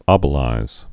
(ŏbə-līz)